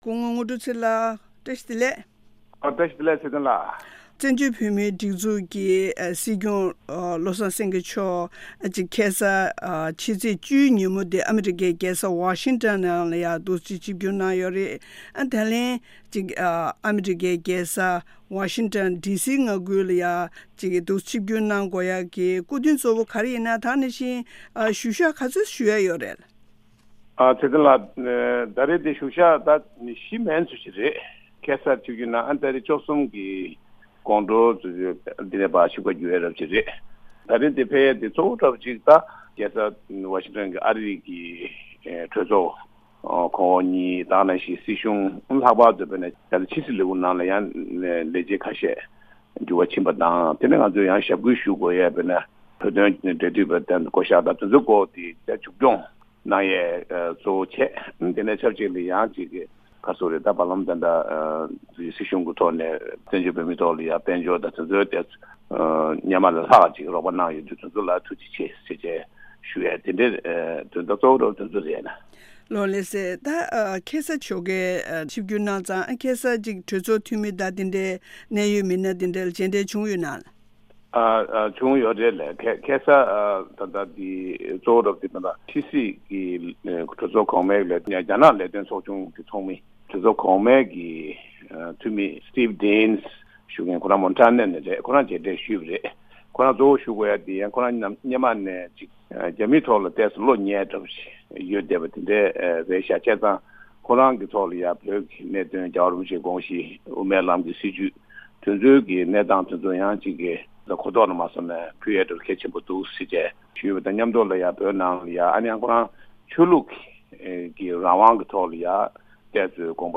གནས་འདྲི་ཞུས་པ་དེ་གསན་རོགས་གནང་།།